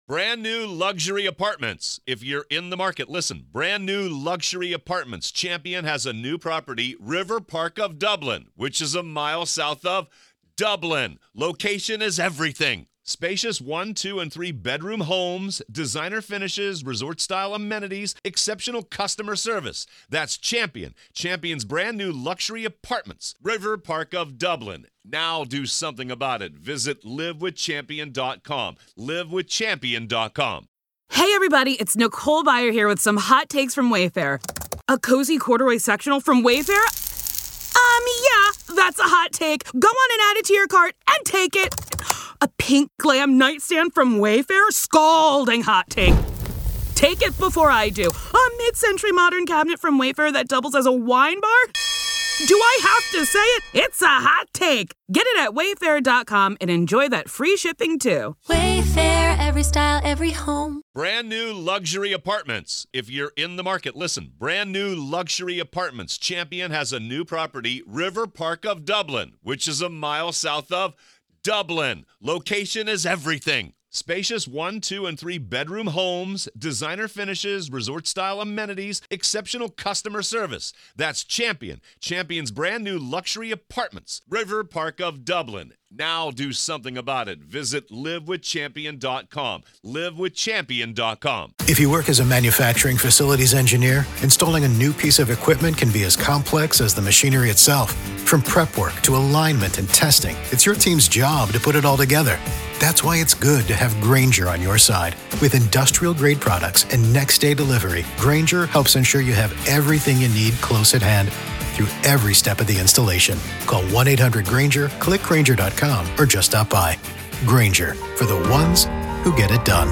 In this raw courtroom testimony